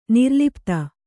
♪ nirlipta